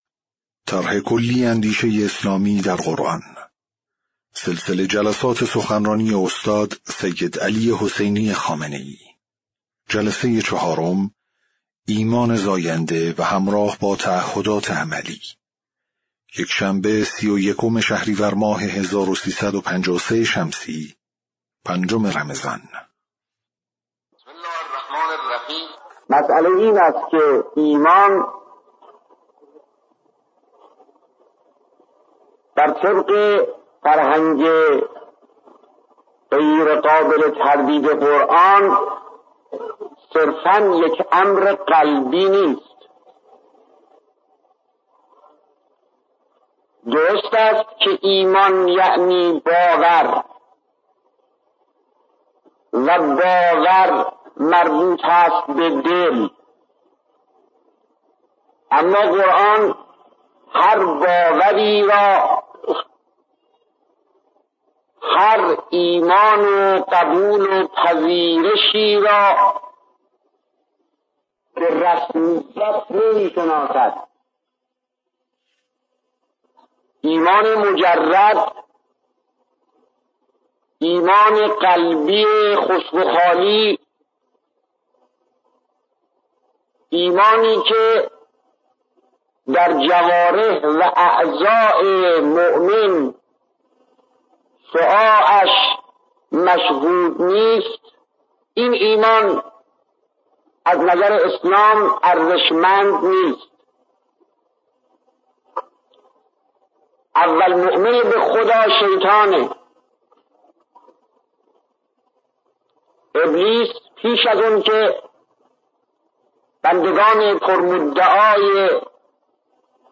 صوت/ جلسه‌چهارم سخنرانی استاد سیدعلی‌ خامنه‌ای رمضان۱۳۵۳